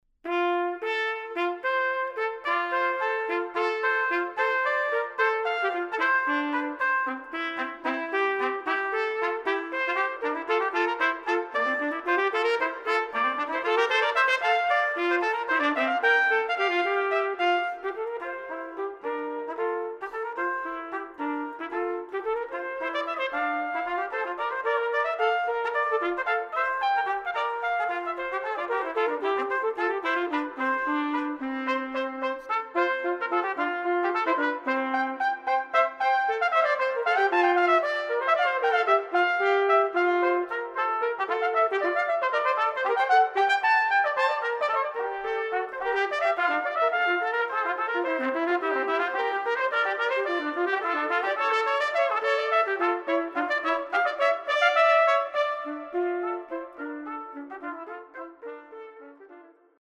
Voicing: Trumpet Duet